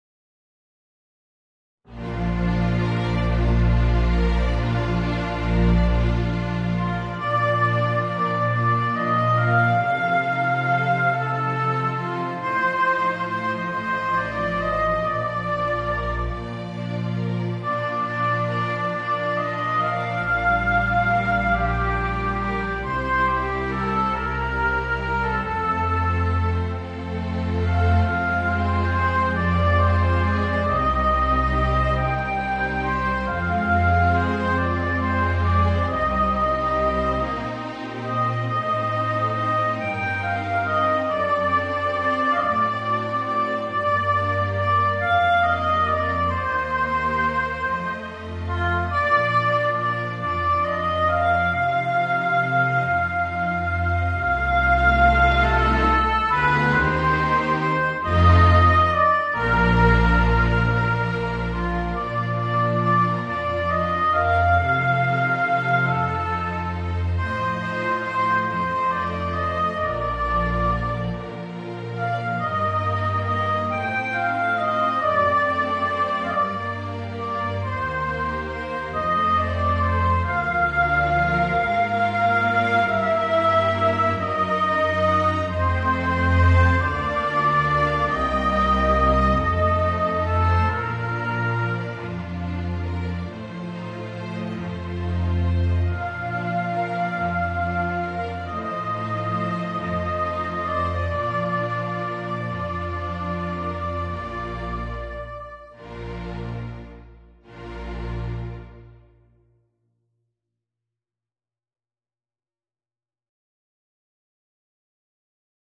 Voicing: Violoncello and String Orchestra